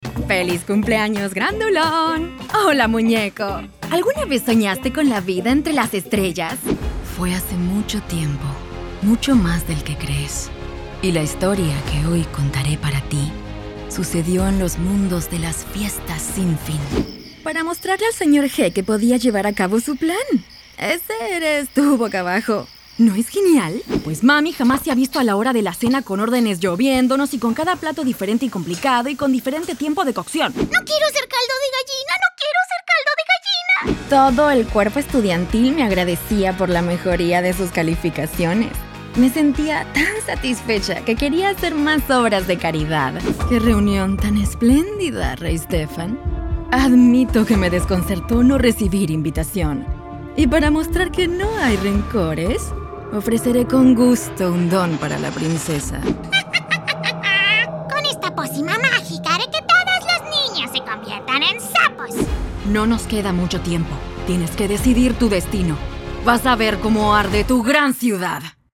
Professional Voice talent specialized in Neutral LATAM spanish
Neutral Latam Spanish Dubbing